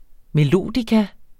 melodica substantiv, fælleskøn Bøjning -en, -er, -erne Udtale [ meˈloˀdika ] Oprindelse fra tysk melodica formentlig navngivet af det tyske firma Matt.